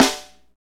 Index of /90_sSampleCDs/Northstar - Drumscapes Roland/DRM_Funk/SNR_Funk Snaresx
SNR FNK S08L.wav